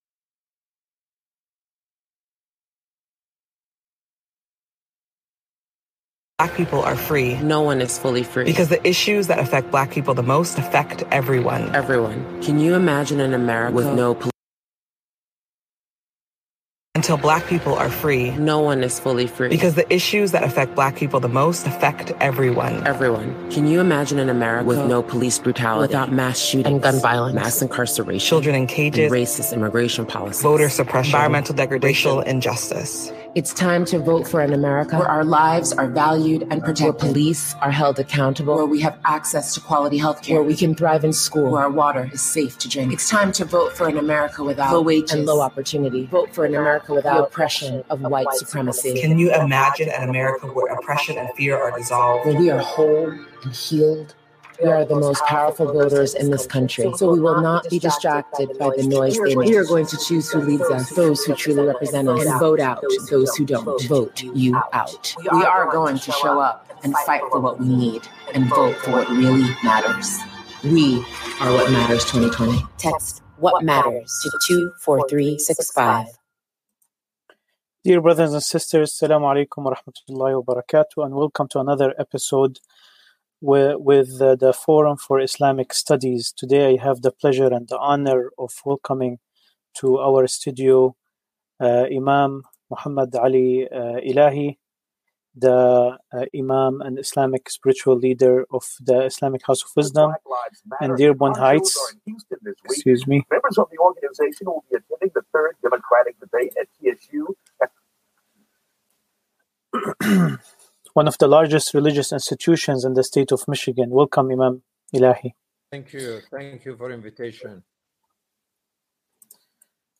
Forum for Islamic Dialogue presents a discussion about Anti-Black Racism in America